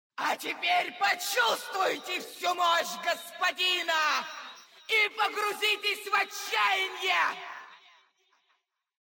Когда босс применяет какую-нибудь свою способность он издает при этом определенные звуки или говорит «дежурные» фразы, порой весьма раздражающие… smile Рассмотрим это на примере босса Цитадели Ледяной Короны – Синдрагосы.